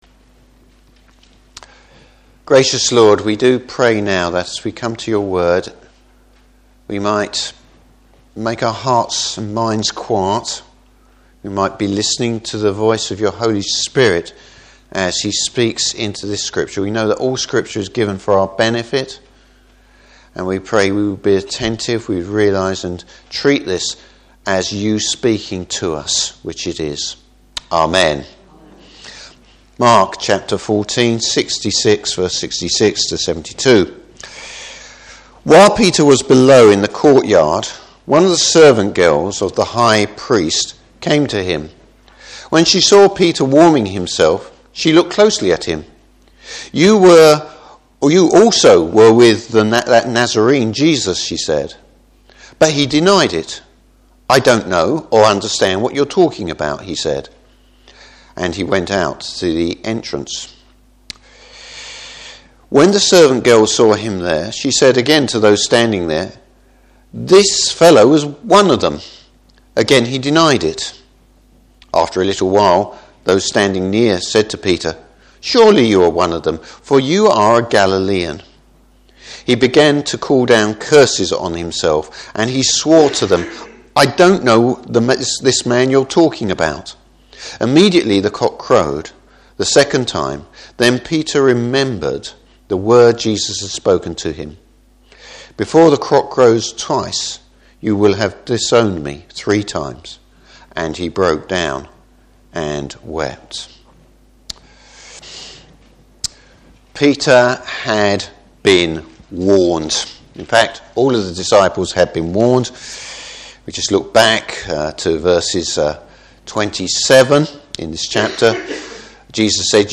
Service Type: Morning Service Why did Peter fail?